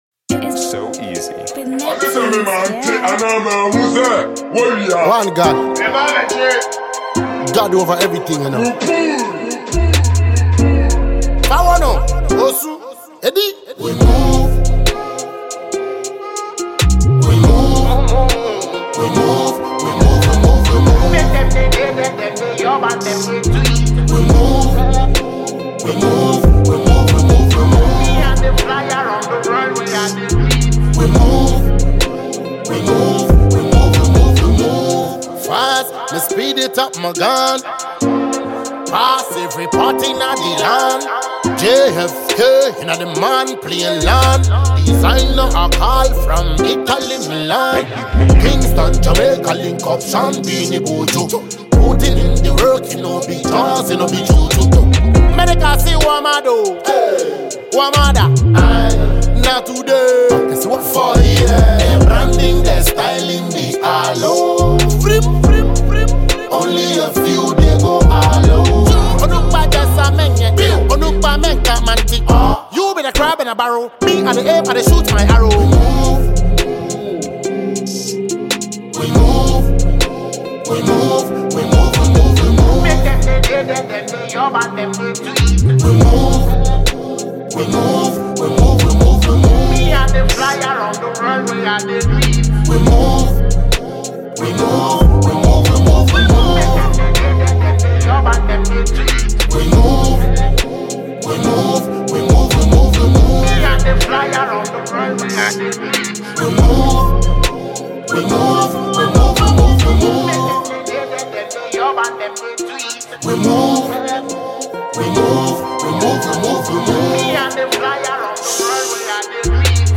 Multiple award-winning Ghanaian Afro-dancehall musician
Drill-inspired freestyle